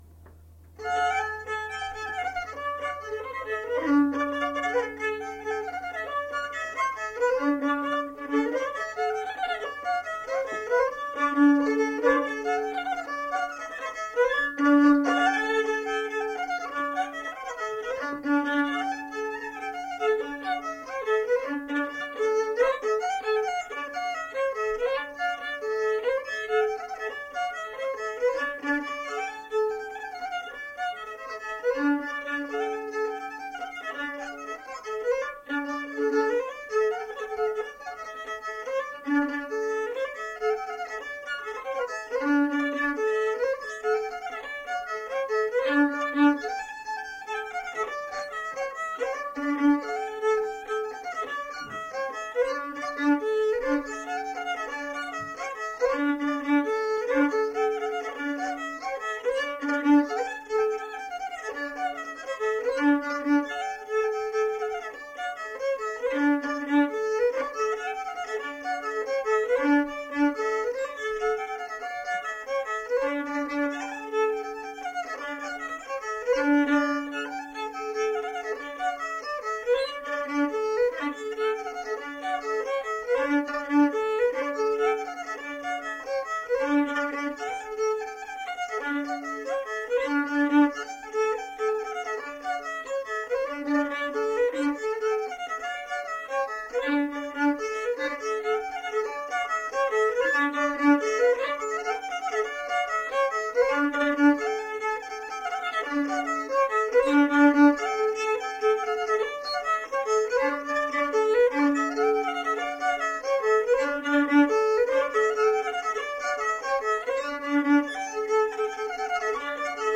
Tipas rauda vestuvių Erdvinė aprėptis Viečiūnai
Atlikimo pubūdis vokalinis